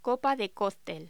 Locución: Copa de cóctel
voz